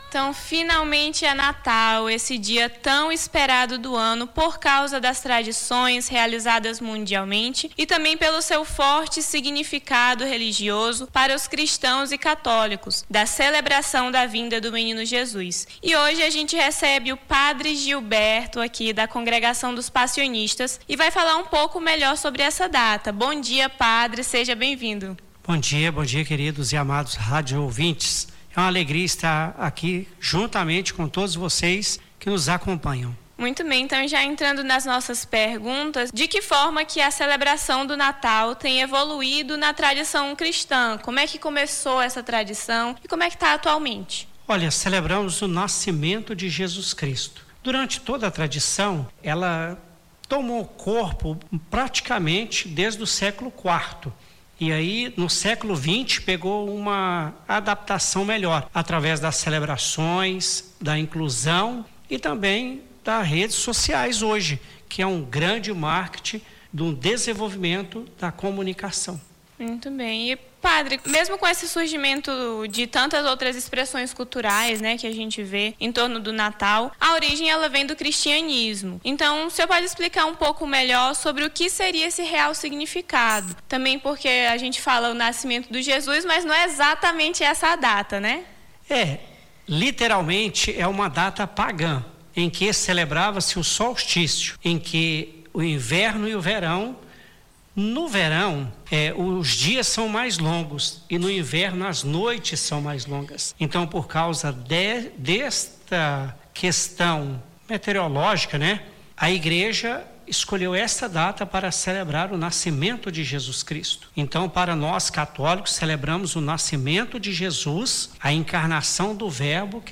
Baixar Esta Trilha Nome do Artista - CENSURA - ENTREVISTA (SIGNIFICADO NATAL) 25-12-25.mp3 Foto: assessoria Facebook Twitter LinkedIn Whatsapp Whatsapp Tópicos Rio Branco Acre Mensagem de Natal católico 2025